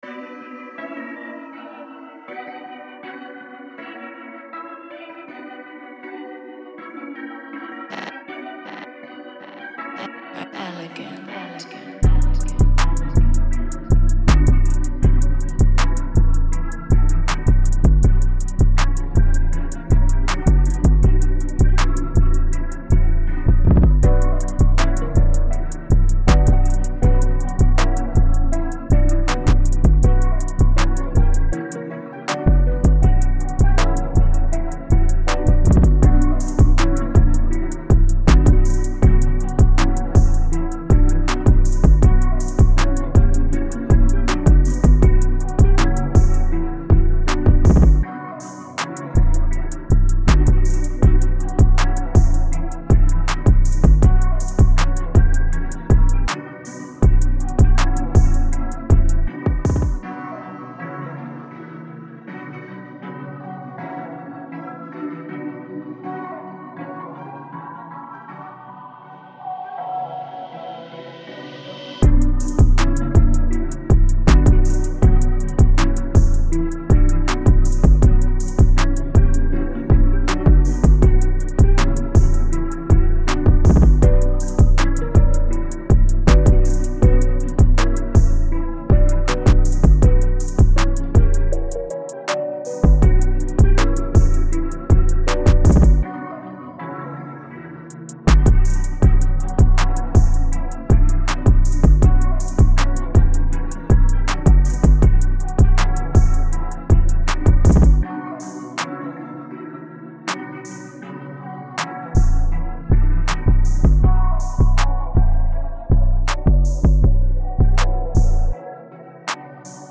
Hip-Hop , Trap